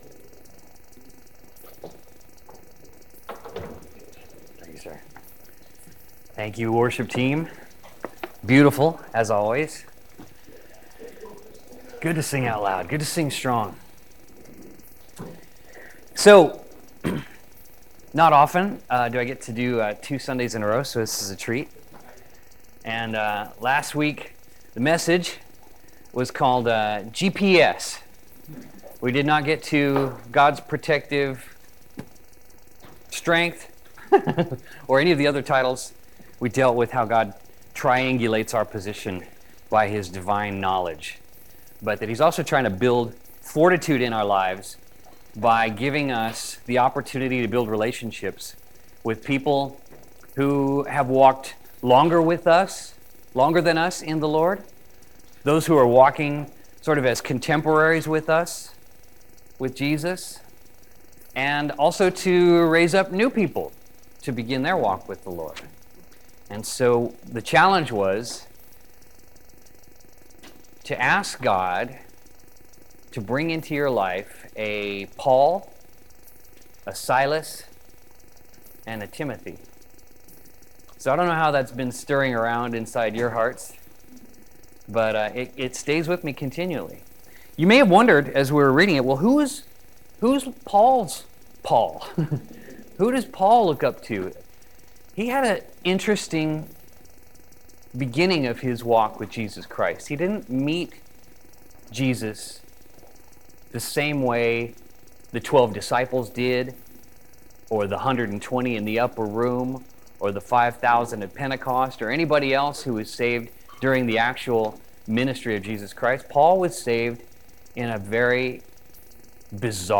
Sermon September 24, 2017